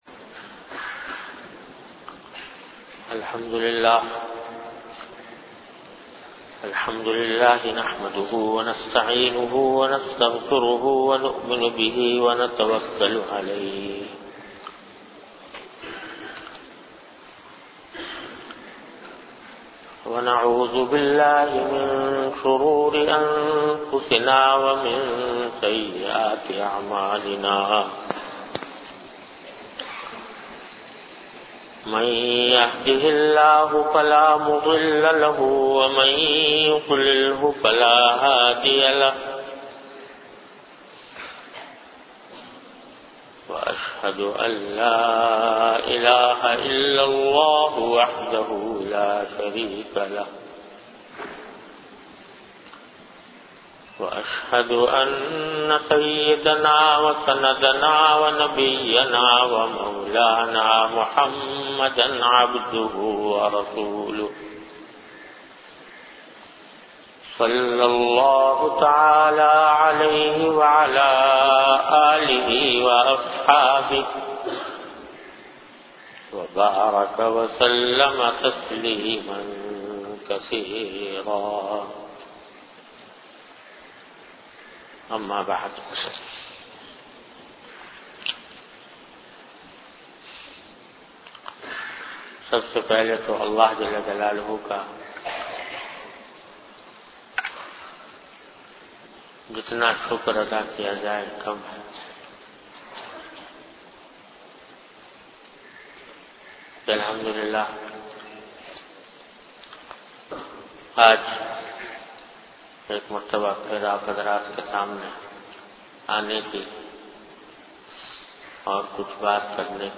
An Islamic audio bayan by Hazrat Mufti Muhammad Taqi Usmani Sahab (Db) on Bayanat. Delivered at Darululoom Karachi.